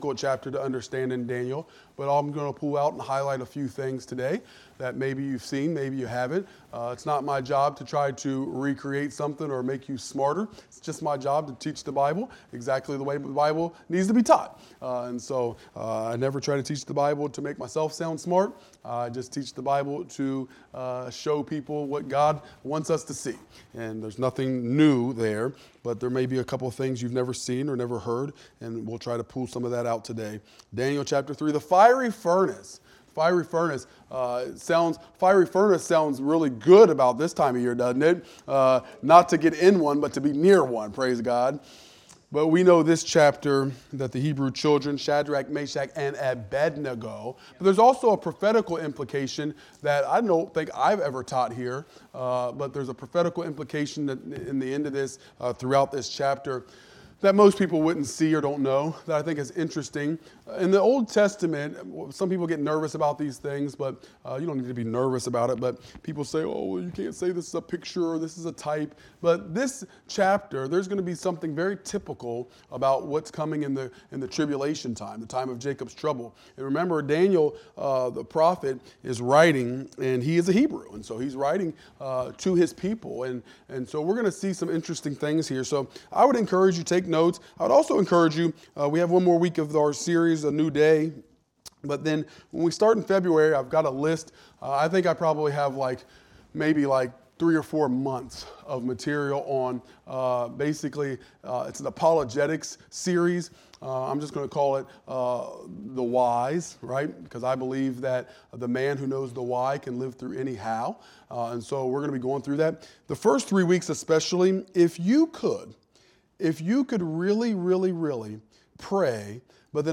Wednesday Midweek Service